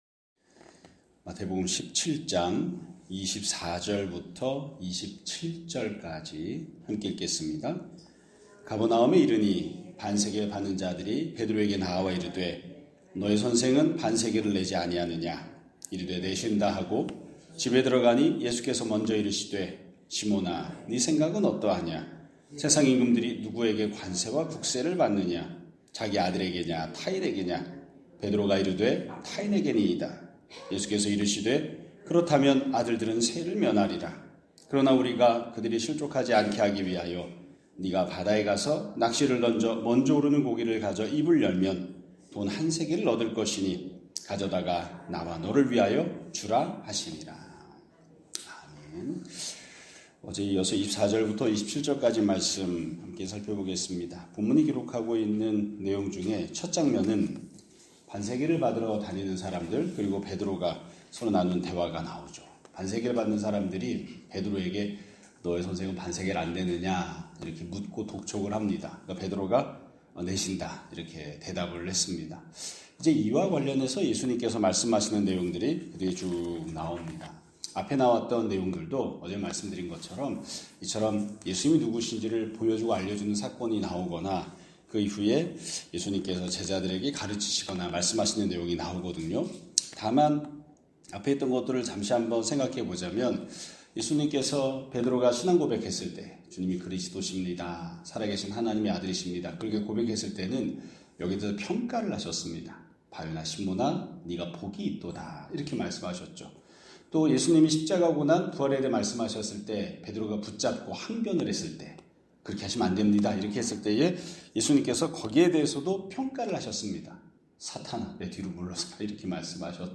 2025년 12월 2일 (화요일) <아침예배> 설교입니다.